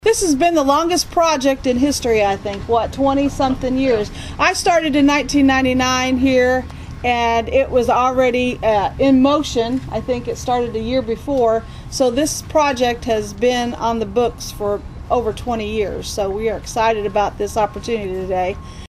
Southeastern Illinois personnel as well as community members instrumental in bringing the new center were on hand for the official ground-breaking ceremony Tuesday afternoon.